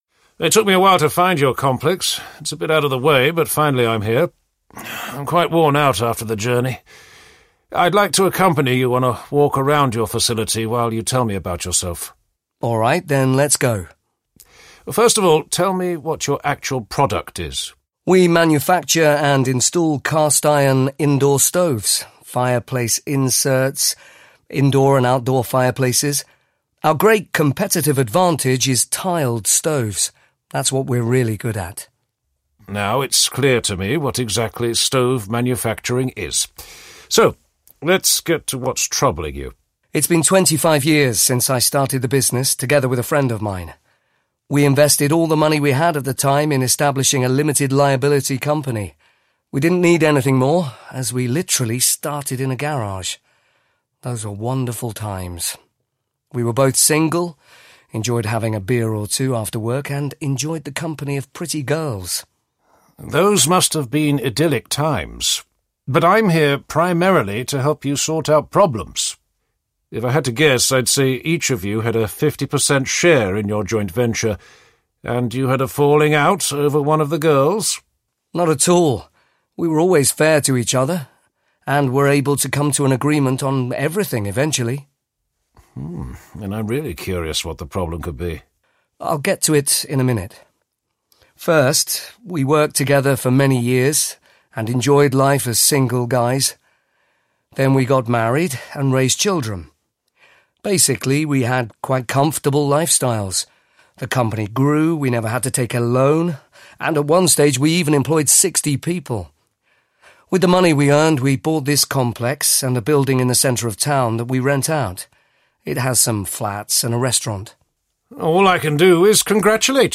Business Risk Buster Intervenes 3 audiokniha
Ukázka z knihy